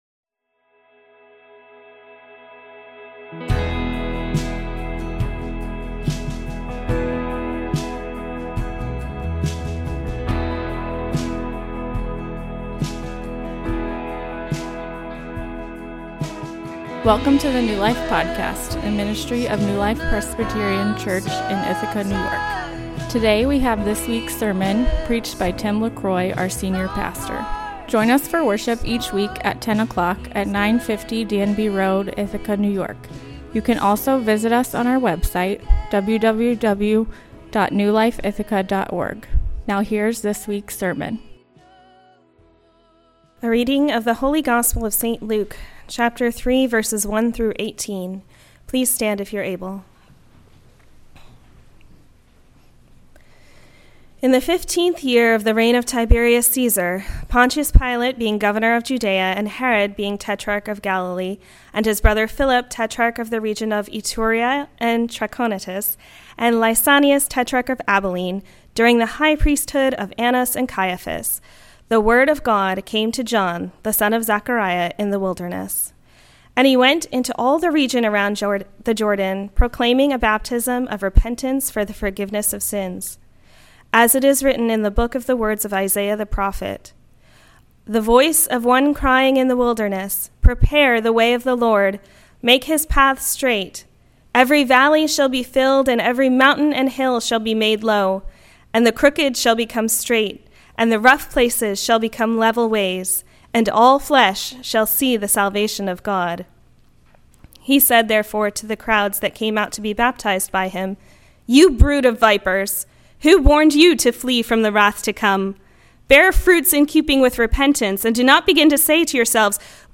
A sermon on Luke 3:1-18 1.